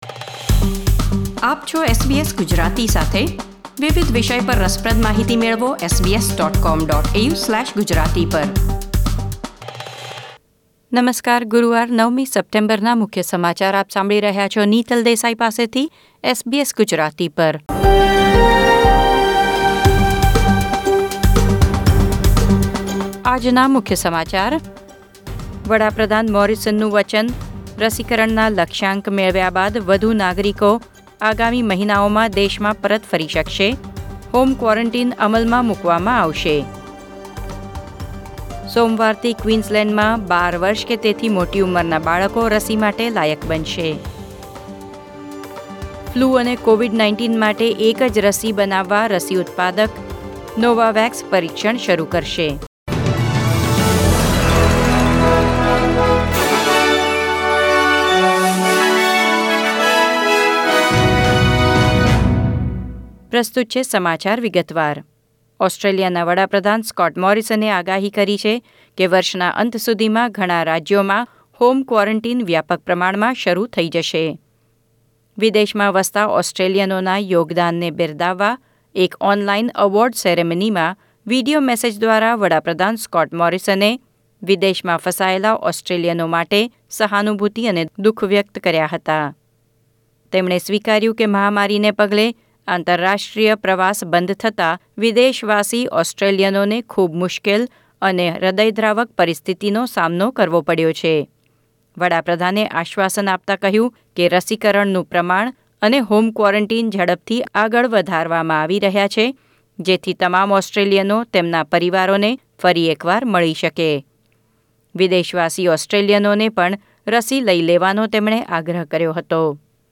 SBS Gujarati News Bulletin 9 September 2021